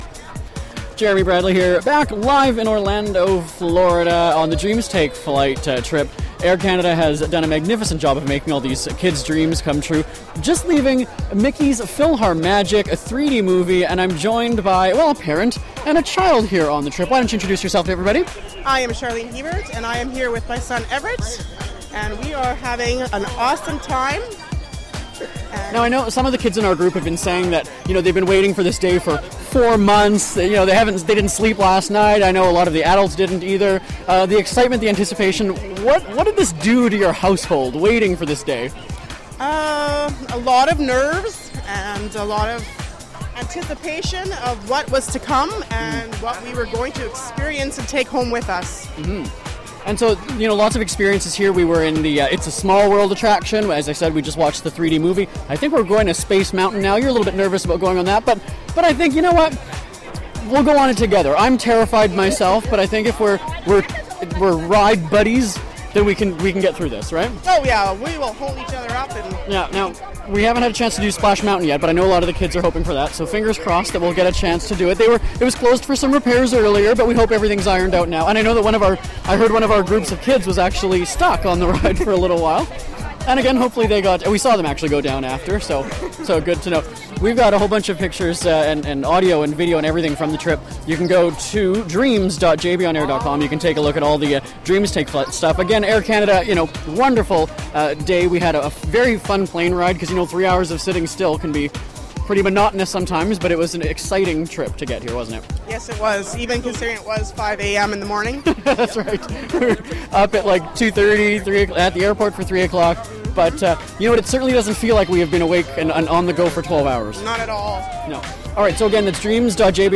SpeakFree Live Event
An excited mom talks about the Dreams Take Flight experience